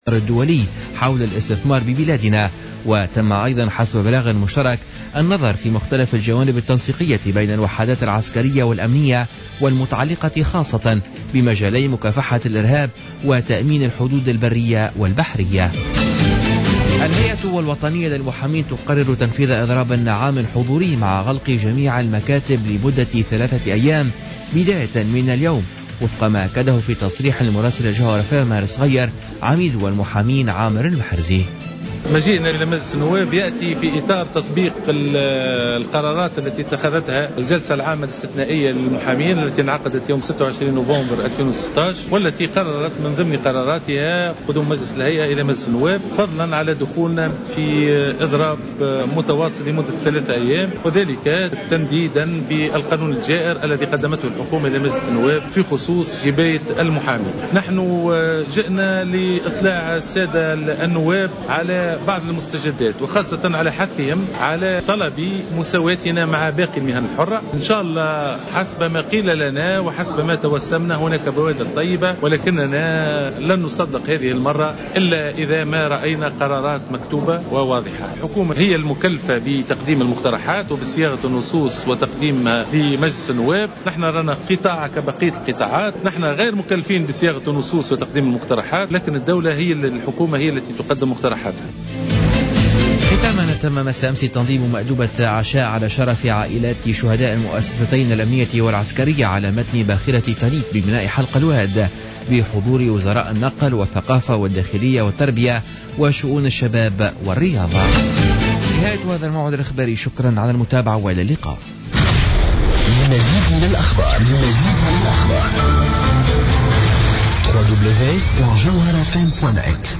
نشرة أخبار منتصف الليل ليوم الثلاثاء 29 نوفمبر 2016